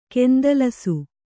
♪ kendalasu